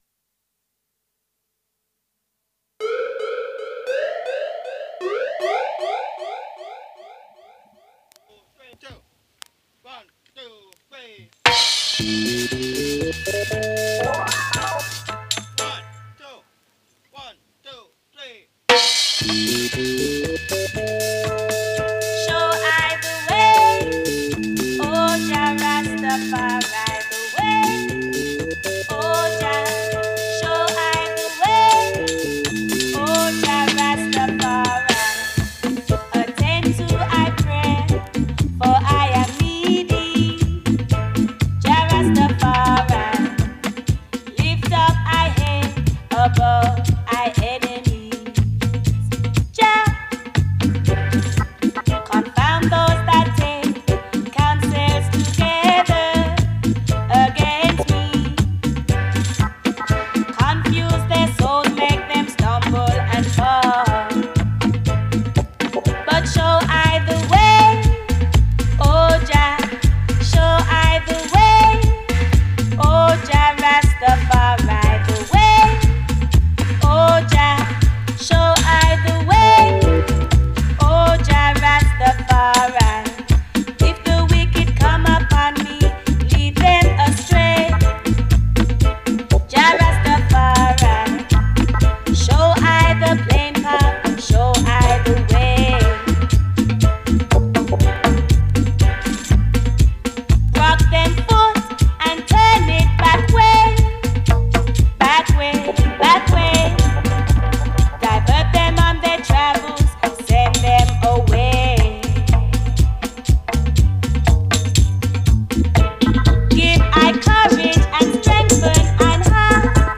1h hour of sweet roots music selection